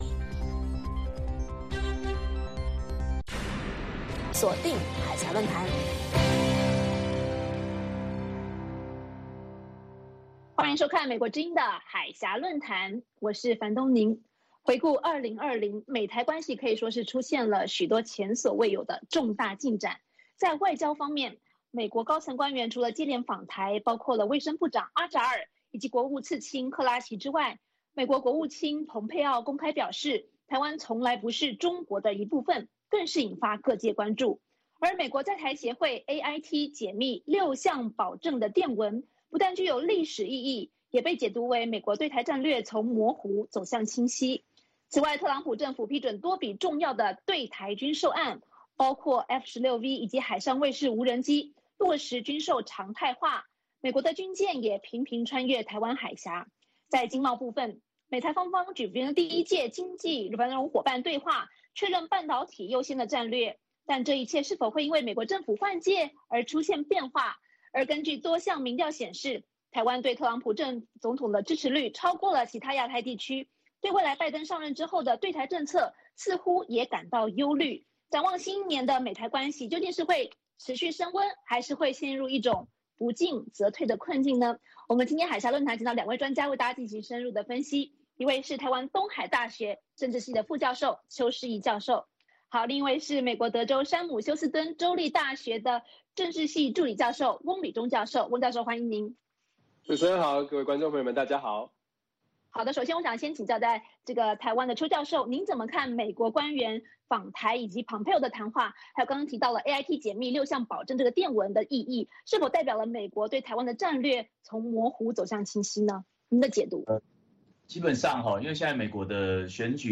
《海峡论谈》节目邀请华盛顿和台北专家学者现场讨论政治、经济等各种两岸最新热门话题。